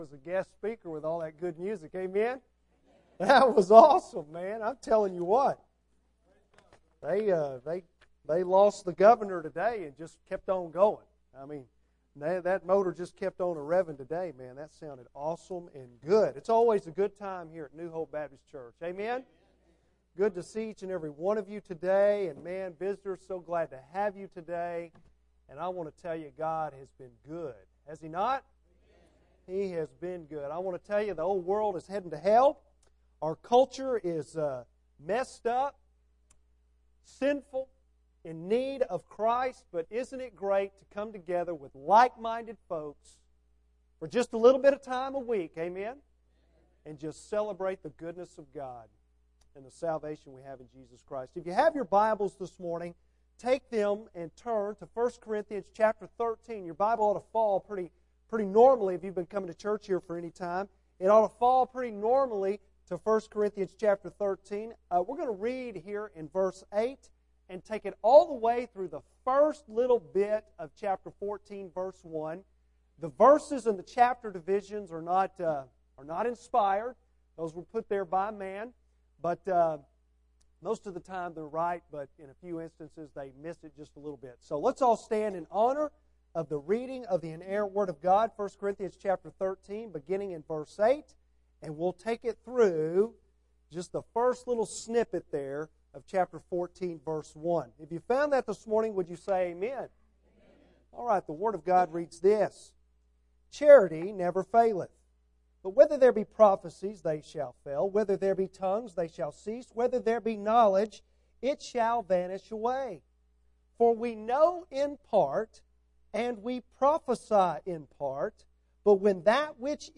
Bible Text: I Corinthians 13:8-13; 14:1 | Preacher